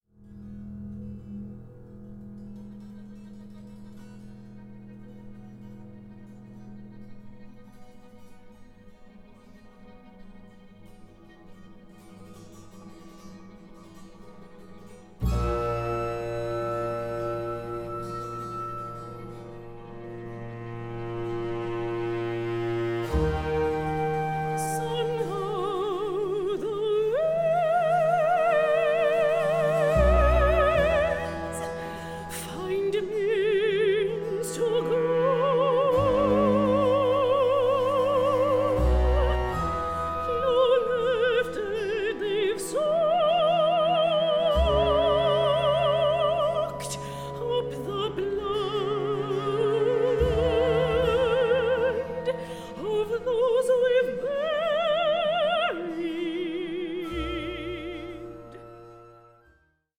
A SURREAL AND DISQUIETING OPERA ON THE AMERICAN DREAM